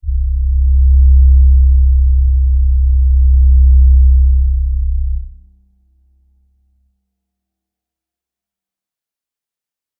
G_Crystal-C2-f.wav